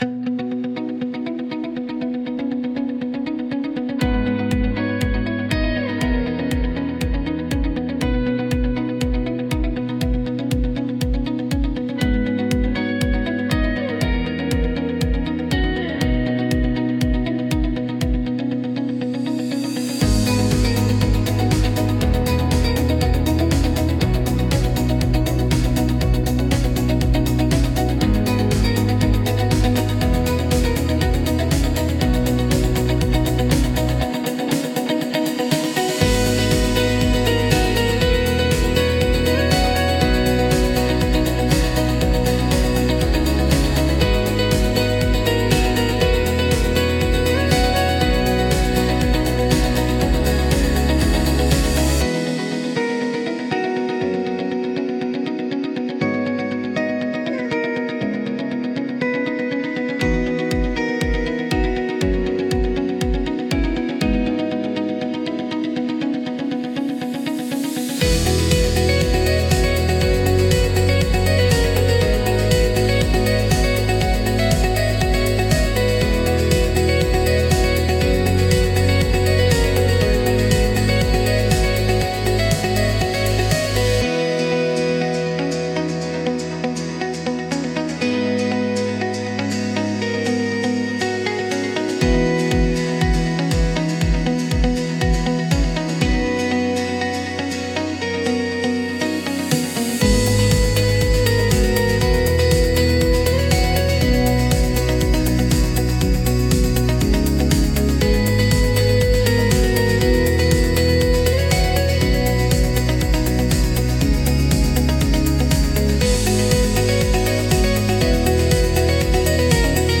聴く人の集中を妨げず、信頼感や安定感を演出するジャンルです